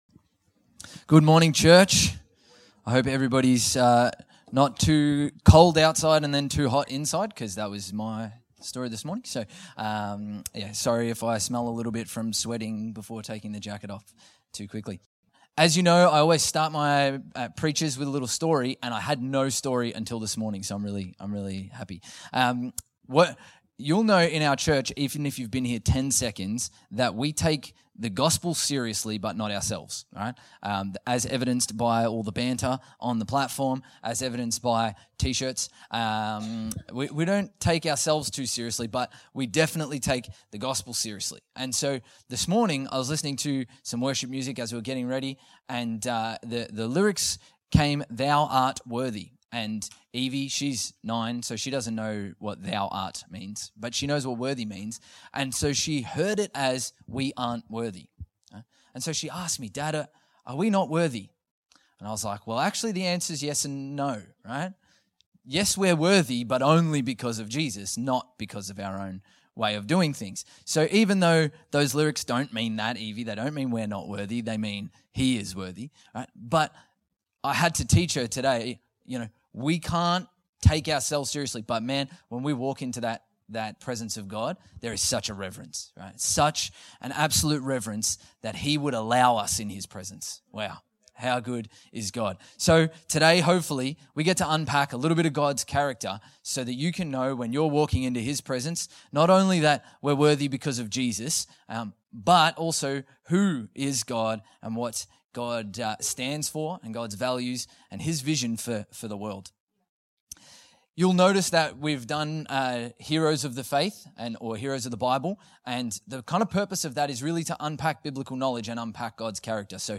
Infinity Church Podcast - English Service | Infinity Church
Current Sermon